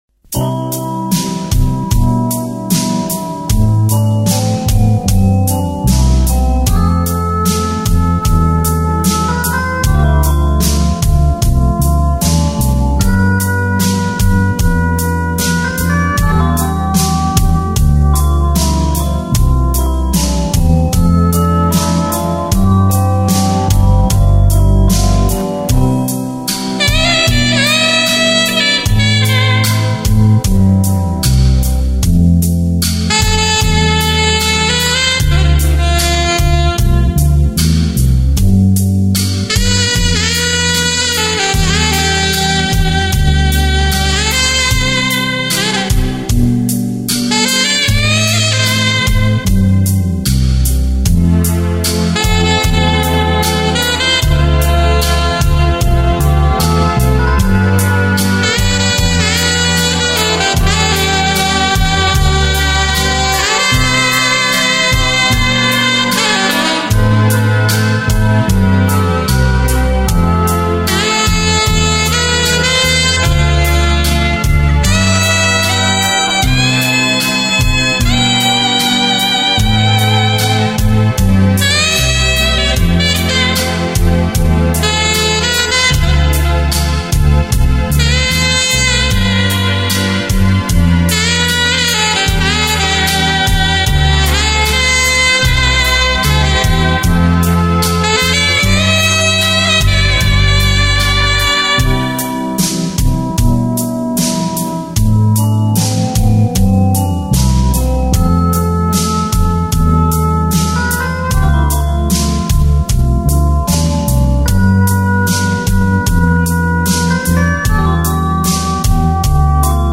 Orchestral Version